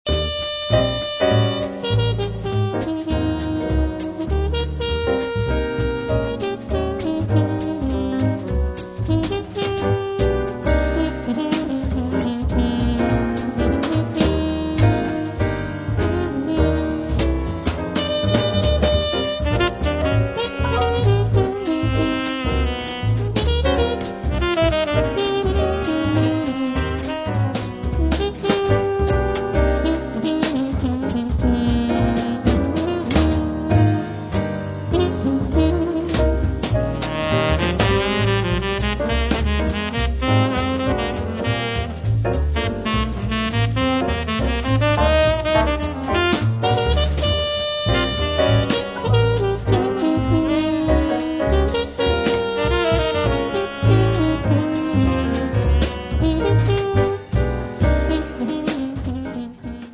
piano
basso
batteria
sax tenore
Tromba